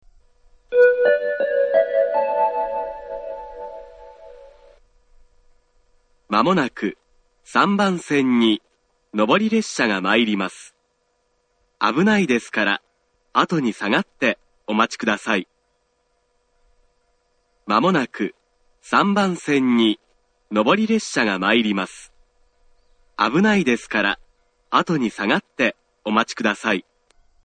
３番線接近放送
tokai-3bannsenn-sekkinn1.mp3